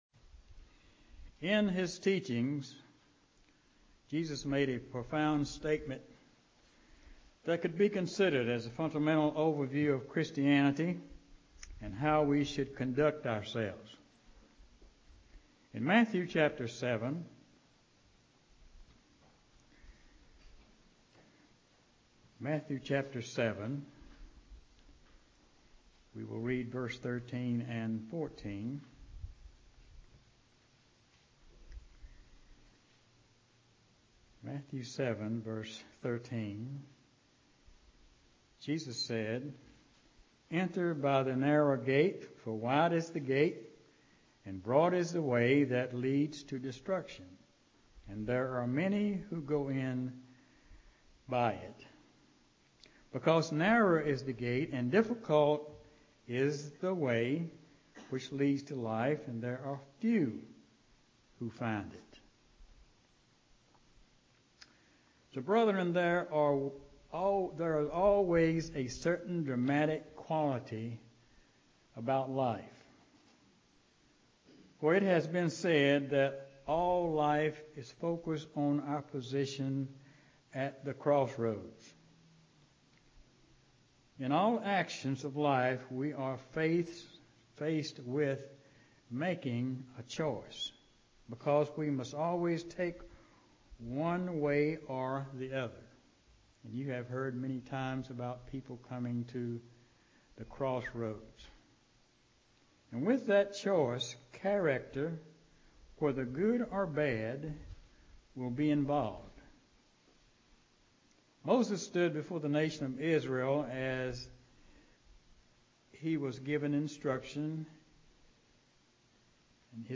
UCG Sermon Studying the bible?
Given in Greensboro, NC